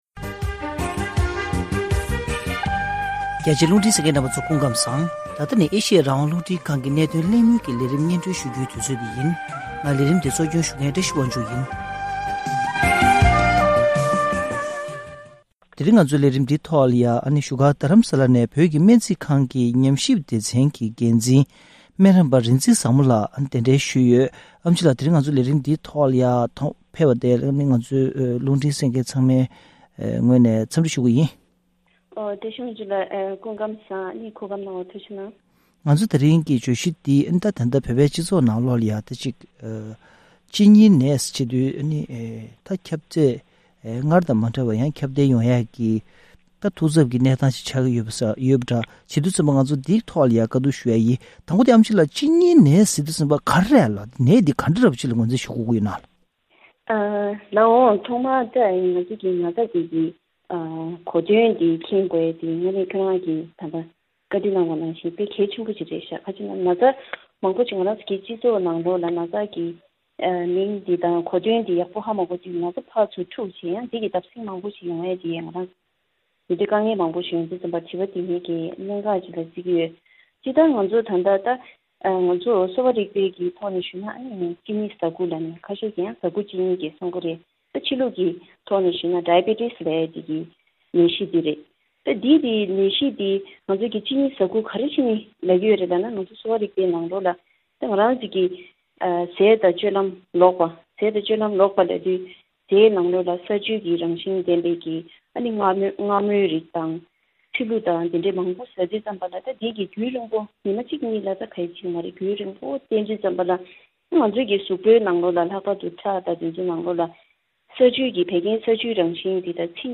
ལྷན་གླེང་བ།